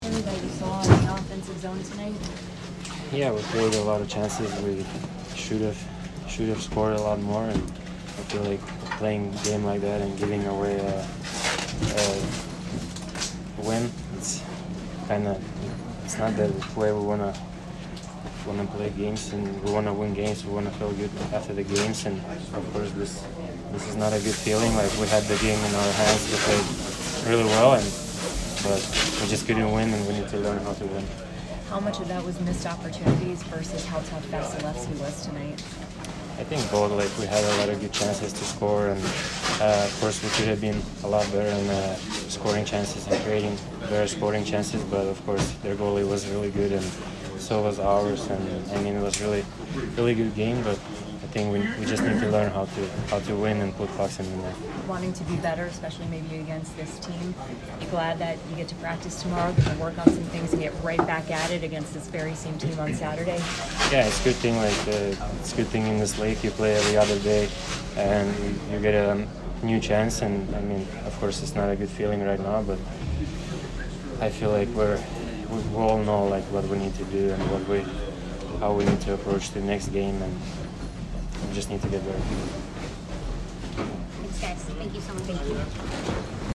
Aleksander Barkov post-game 10/3